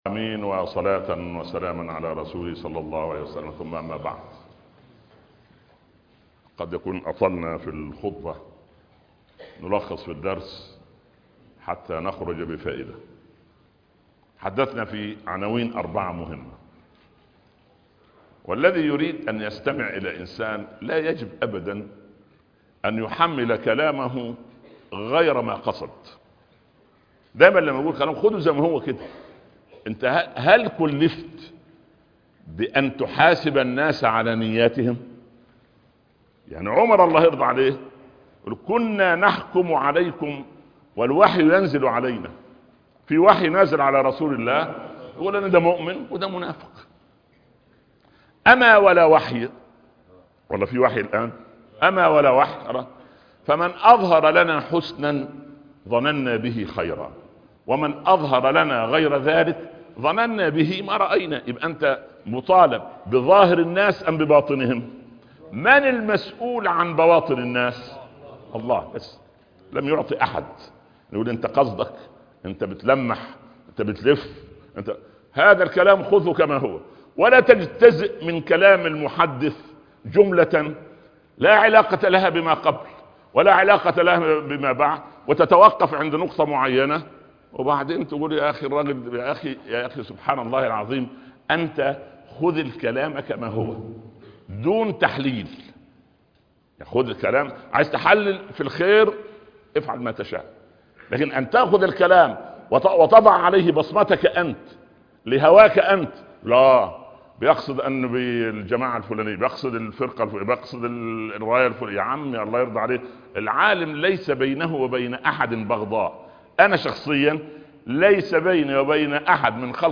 فقه الدراية ( درس الجمعة) - الشيخ عمر بن عبدالكافي